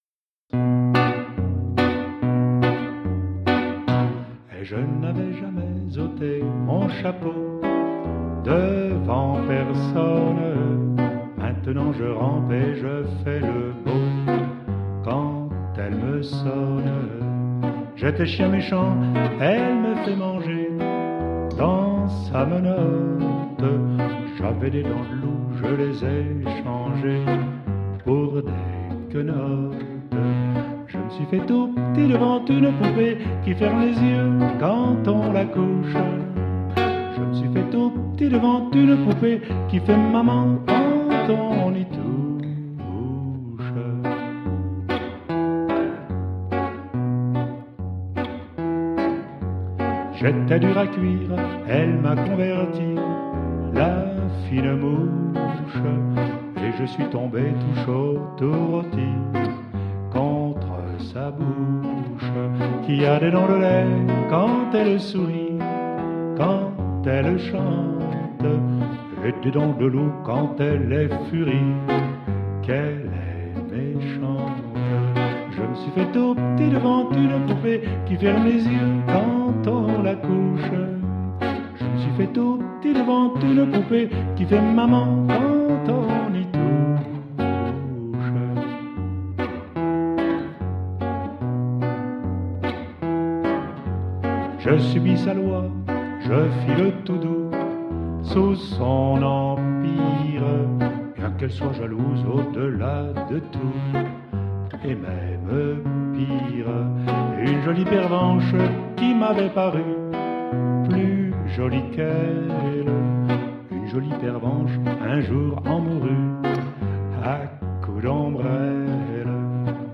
Enregistré le 11 février 2014 à l'occasion d'un spectacle
et les erreurs sont aussi le charme du direct...